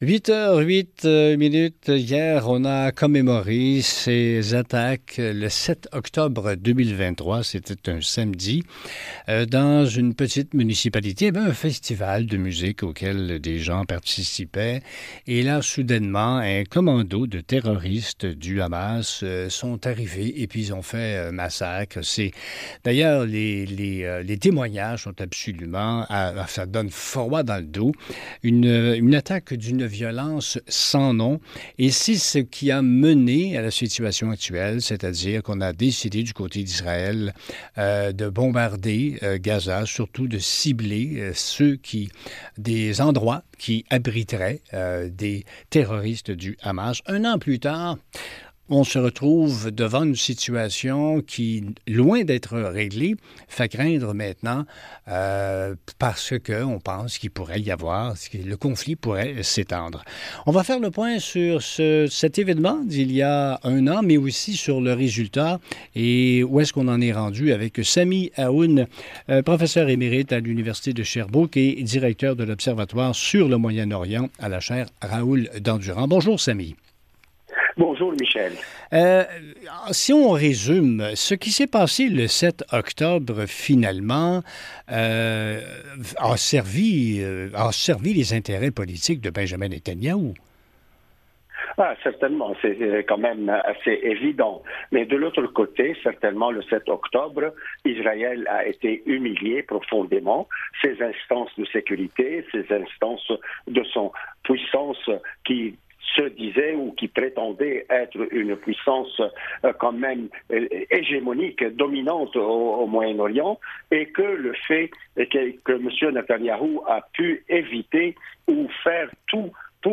Entrevues radiophoniques